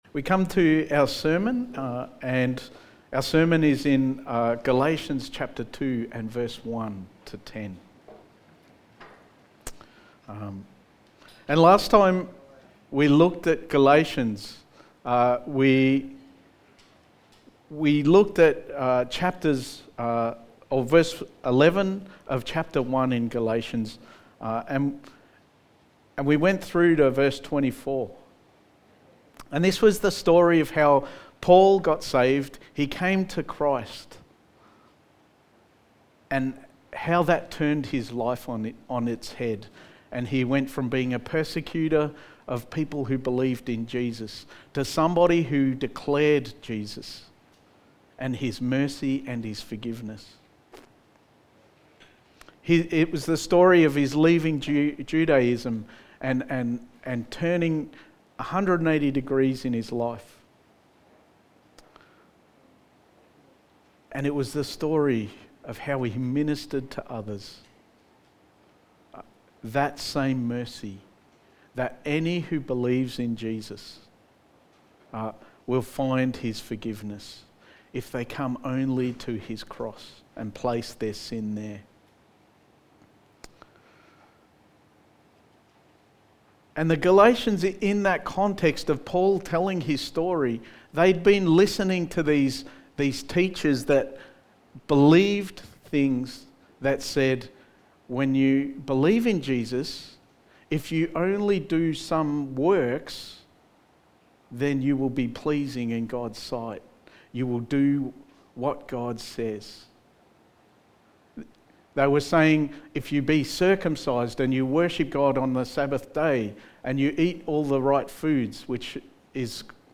Sermon
Service Type: Sunday Morning Sermon